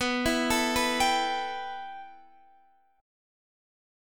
B7sus4#5 chord